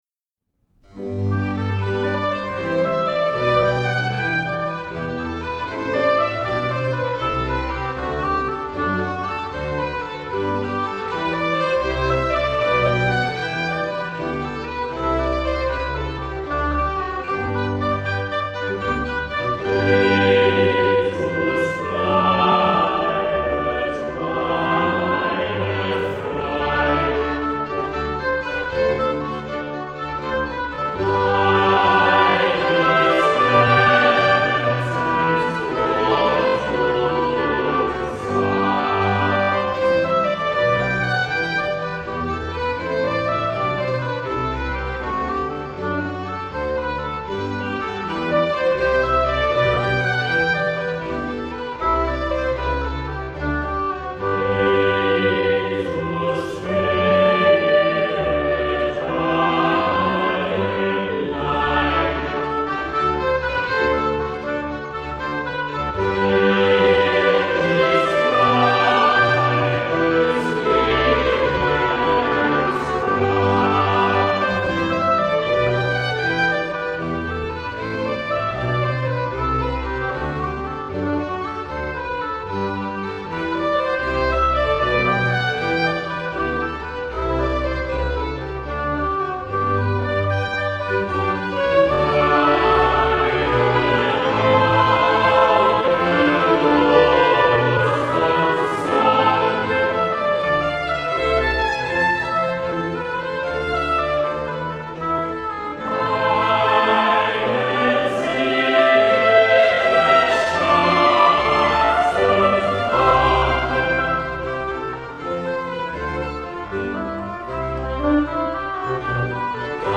Jesus bleibet meine Freude: langzaam